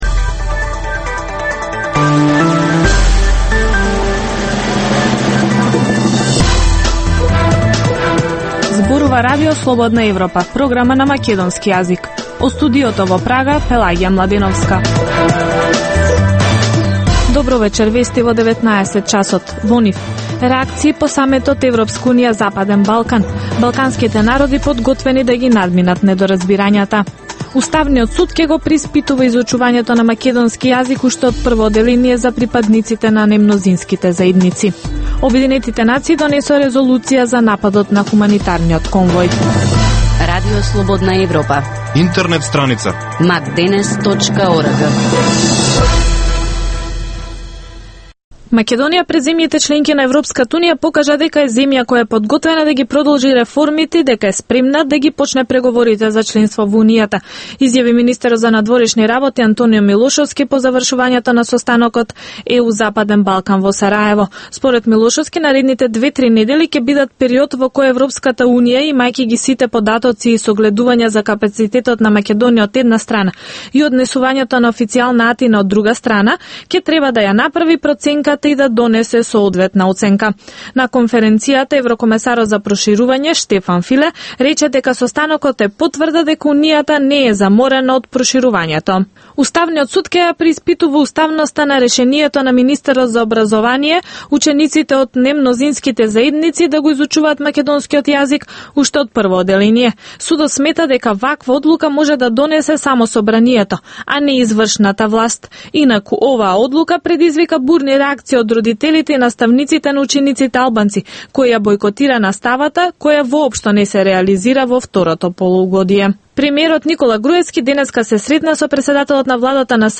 Преглед на вестите и актуелностите од Македонија и светот, како и локални теми од земјата од студиото во Прага.